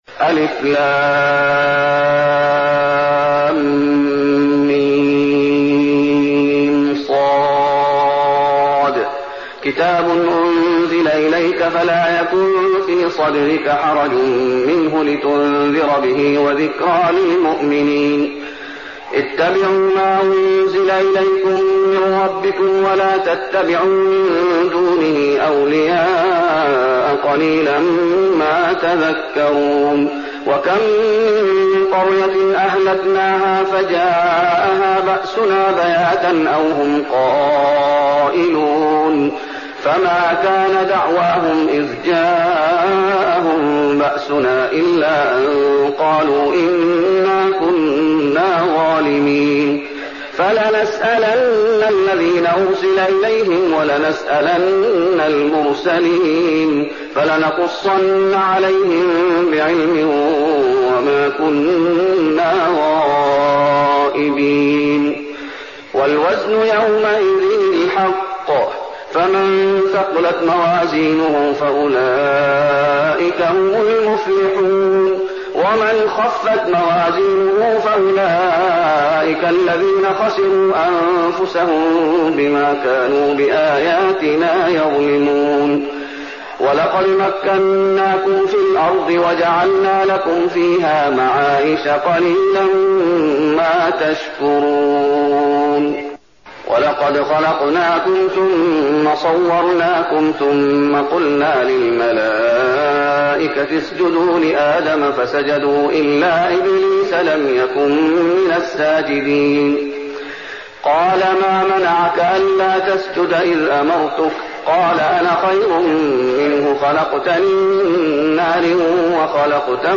المكان: المسجد النبوي الأعراف